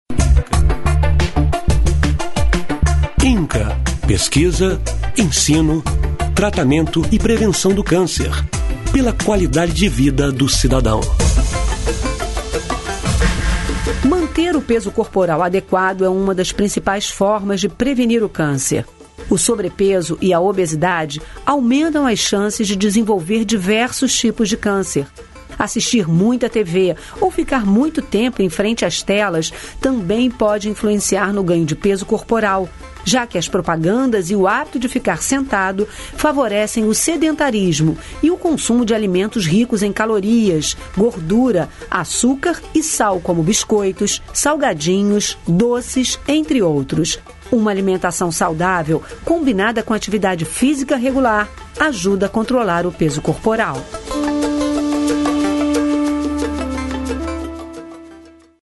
Spot – Obesidade